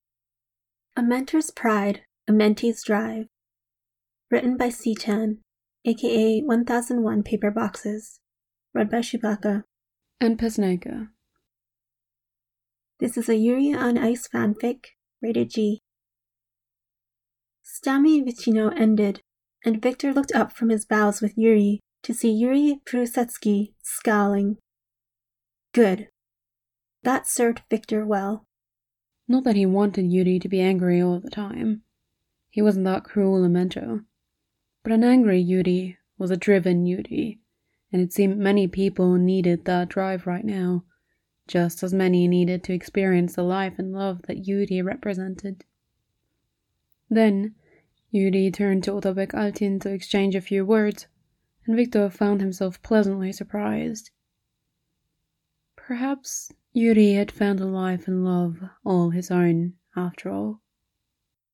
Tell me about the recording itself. This was recorded for the Tiebreaker round of Voiceteam 2024.